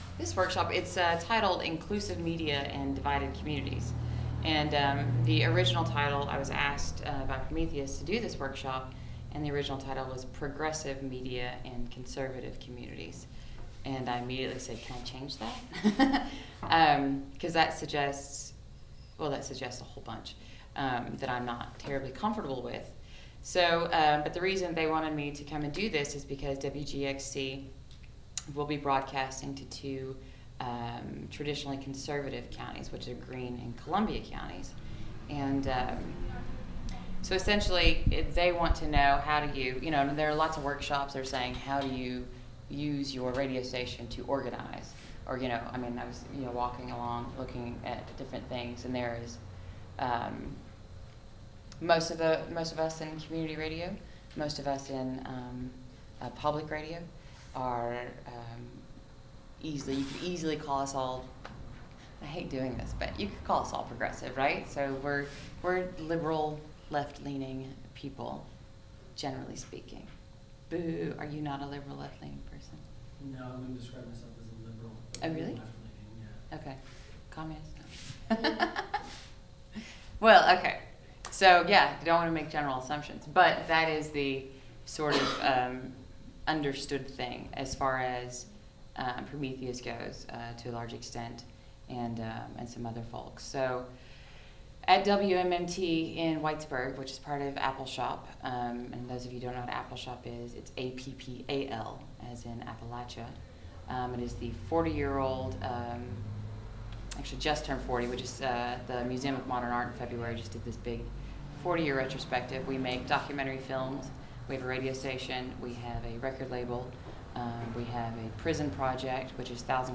WGXC/Prometheus Radio Project Station Barnraising: Sep 24, 2010 - Sep 26, 2010